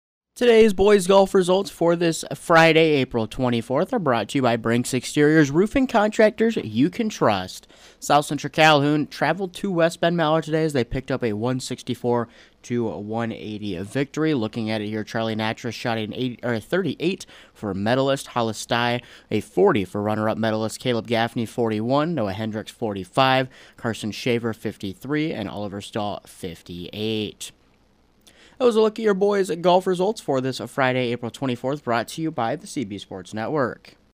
Below is an Audio Recap of Boys Golf Results from Friday, April 24th